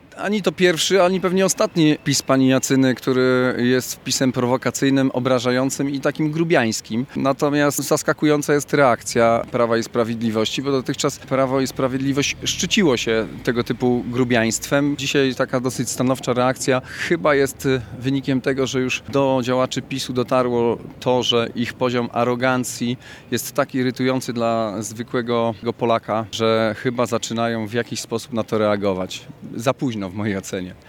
Marszałek Olgierd Geblewicz uważa, że reakcja władz PiS na kontrowersyjne wpisy radnej jest stanowczo za późna.